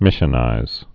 (mĭshə-nīz)